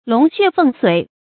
龍血鳳髓 注音： ㄌㄨㄙˊ ㄒㄩㄝˋ ㄈㄥˋ ㄙㄨㄟˇ 讀音讀法： 意思解釋： 比喻高貴的血統。